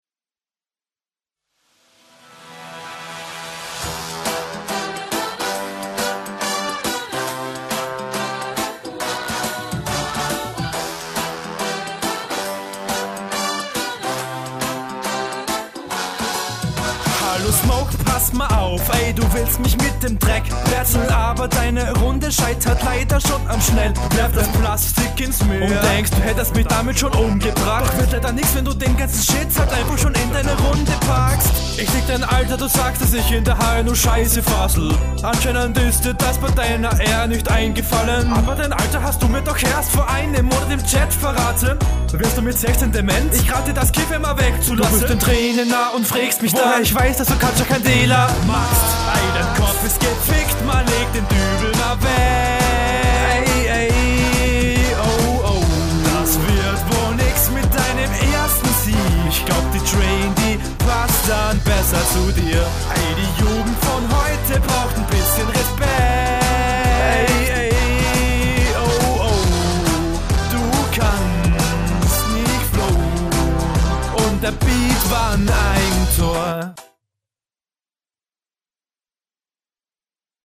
Oh damn du kommst sehr gut auf dem Beat.
Direkt mehr Druck als der Gegner in der Stimme!